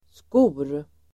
Uttal: [sko:r]